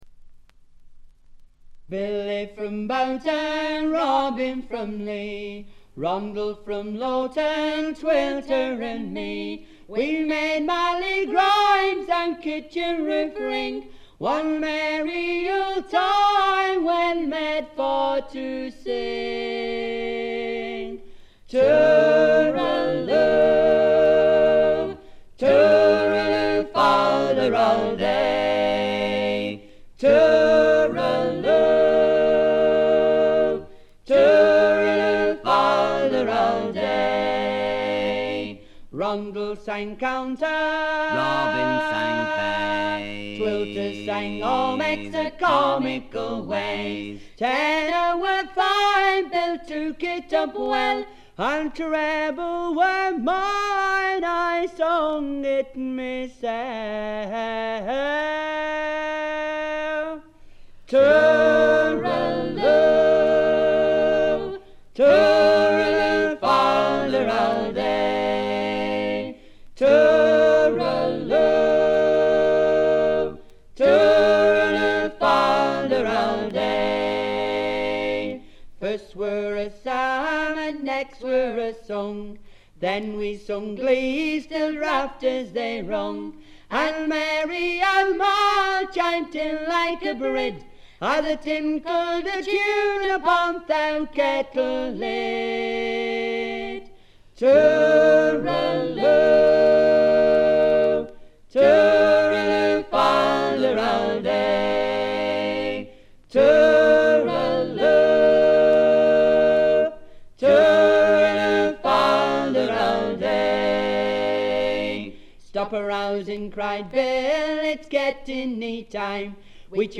部分試聴ですが、ほとんどノイズ感無し。
若々しさ溢れるヴォーカルがとてもよいですね。
ごくシンプルな伴奏ながら、躍動感が感じられる快作！
コンサーティナの哀愁と郷愁をかきたてる音色がたまりません。
試聴曲は現品からの取り込み音源です。